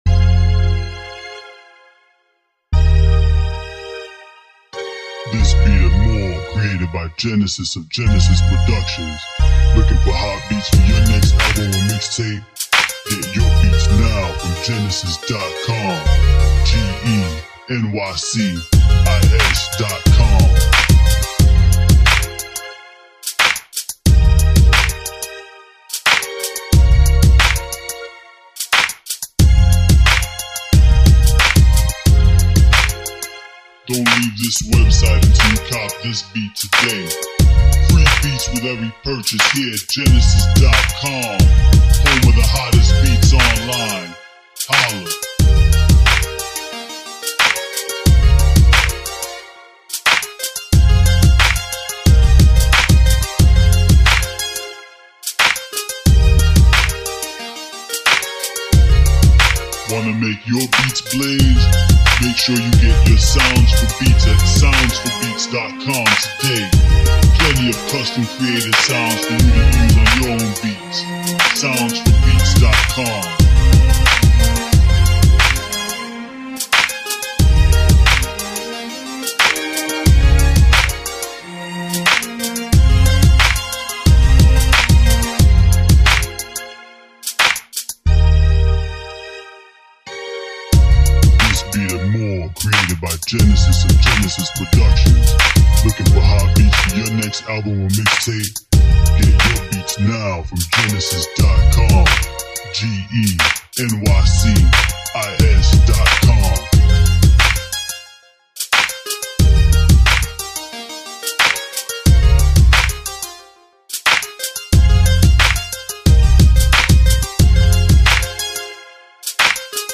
R&B Instrumentals